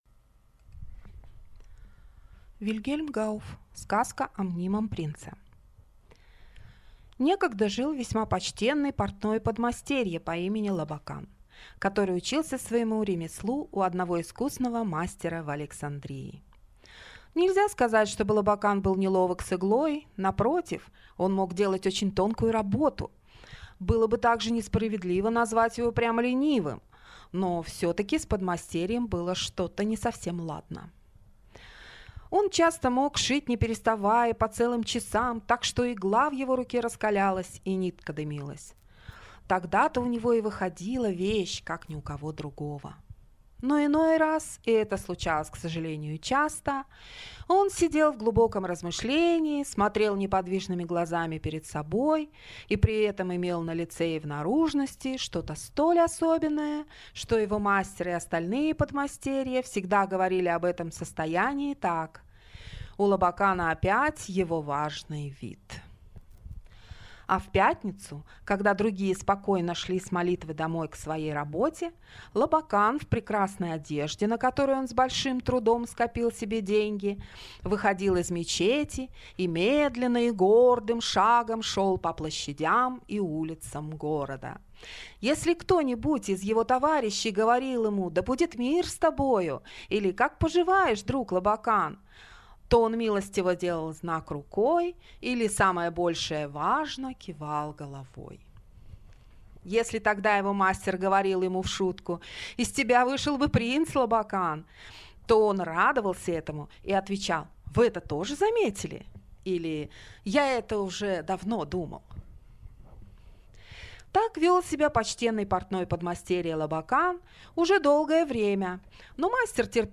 Аудиокнига Сказка о мнимом принце | Библиотека аудиокниг